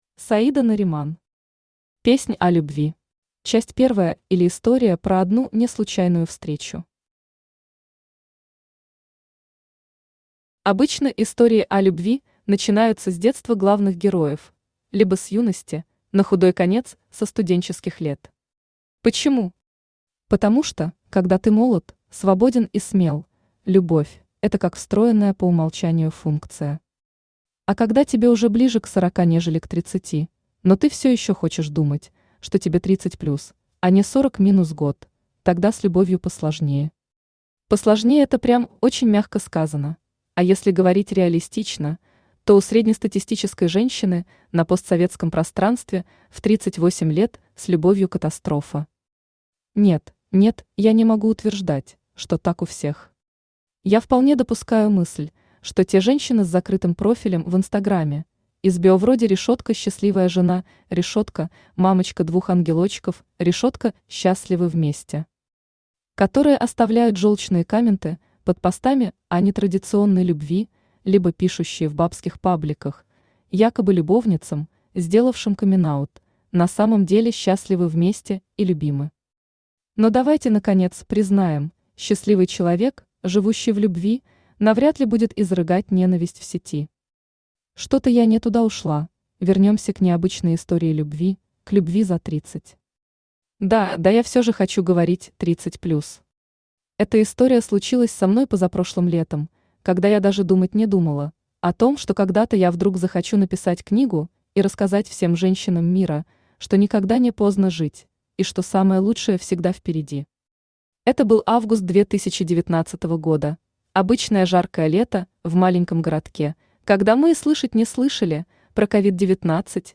Аудиокнига Песнь о любви | Библиотека аудиокниг
Aудиокнига Песнь о любви Автор Саида Нариман Читает аудиокнигу Авточтец ЛитРес.